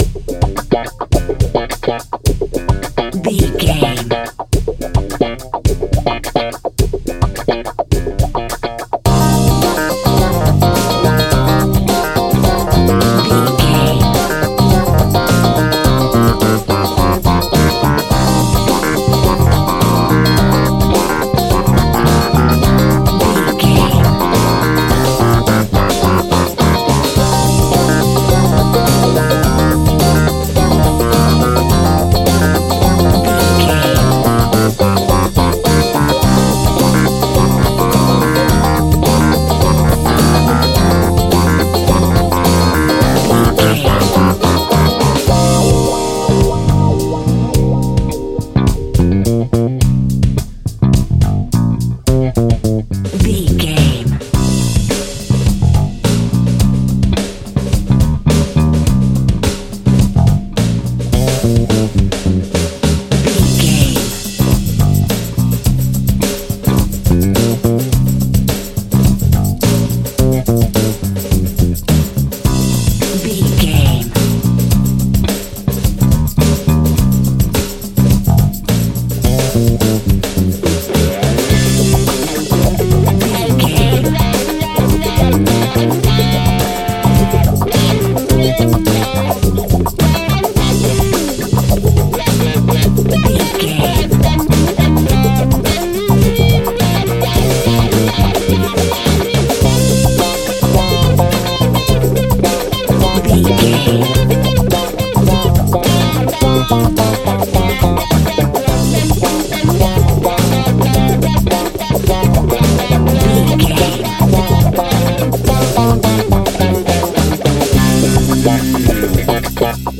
Aeolian/Minor
lively
electric guitar
electric organ
drums
bass guitar
saxophone
percussion